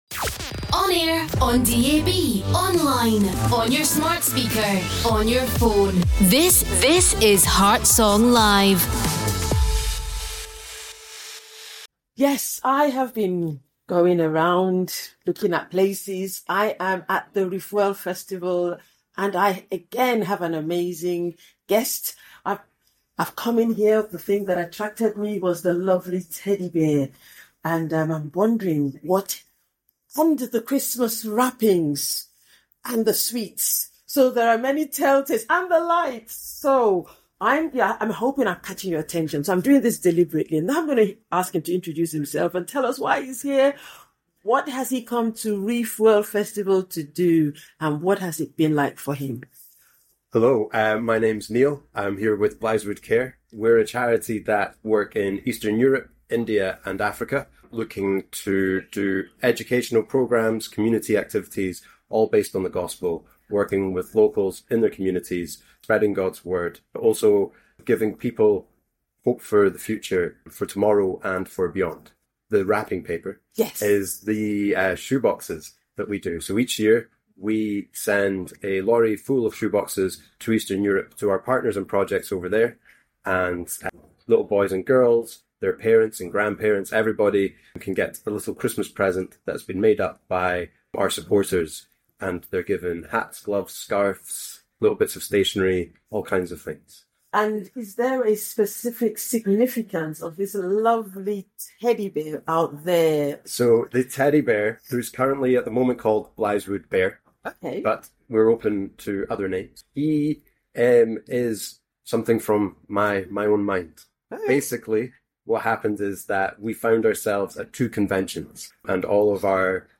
That’s exactly what drew our attention at the Refuel Festival.
Refuel-Blythsswood.mp3